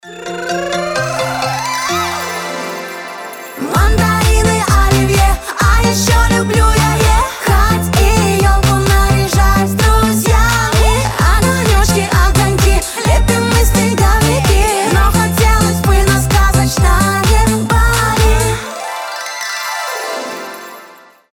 • Качество: 320, Stereo
веселые
озорные